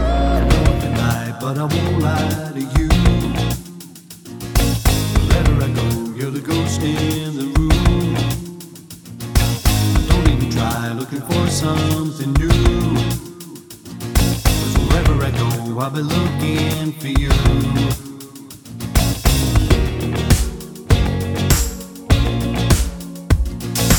no Backing Vocals Pop (2010s) 3:00 Buy £1.50